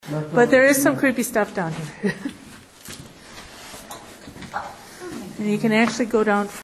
Old dorms basement
I am commenting about "creepy stuff" when something creepy comes through on my recorder.